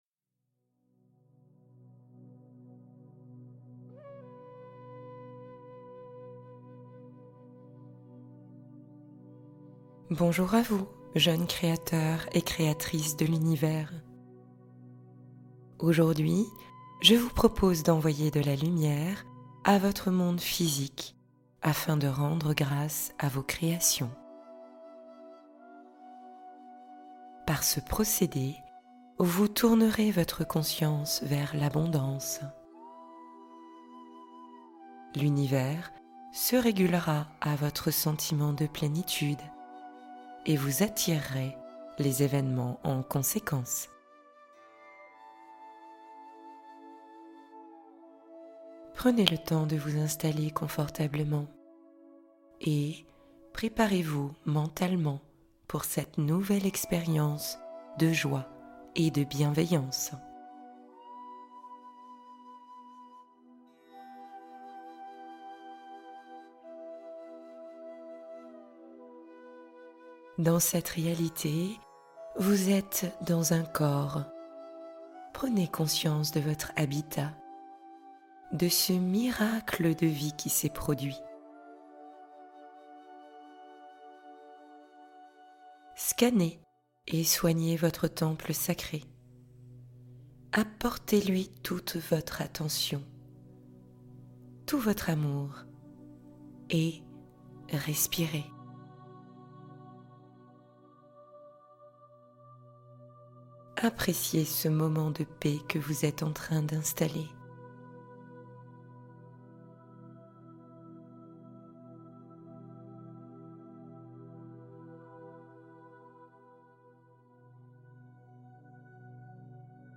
Suivez le chemin de lumière qui vous attend | Méditation guidée d'élévation spirituelle